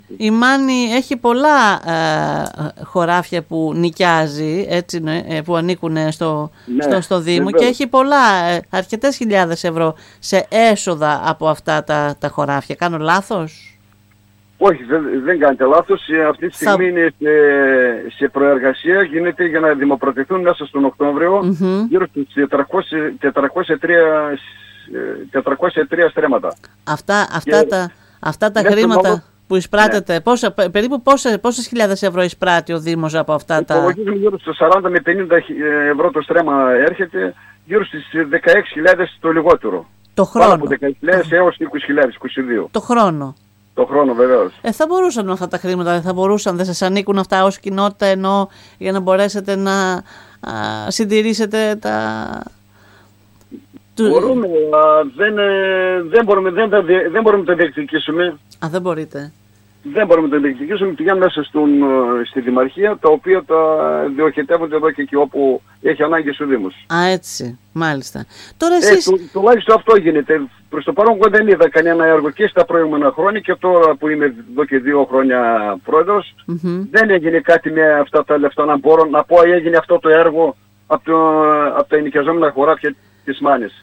Ο Πρόεδρος της κοινότητας Μάνης Δημήτρης Γκιντίδης,  βρίσκεται σε απόγνωση και καταθέτει  στην ΕΡΤ Ορεστιάδας την εικόνα που επικρατεί στο χωριό του, όπου ένα κάμπος 7.000 στρεμμάτων είναι ανενεργός.